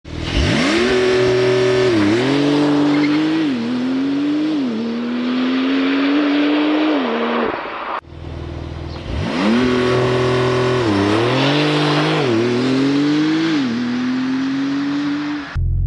Porsche 911 Turbo S Accelerator and 0 to 300 Sound